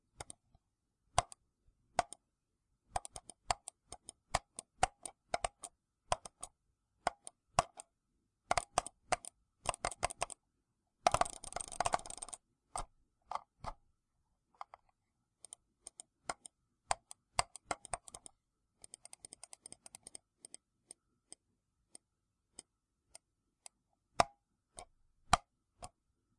鼠标点击
描述：以不同的速度进行一系列干净的鼠标点击。左键和右键；索引和中间。有一点滚轮的动作也扔进去了。 用蓝色雪球在设置2上记录，以消除噪音。
标签： 变化 点击 鼠标 计算机
声道立体声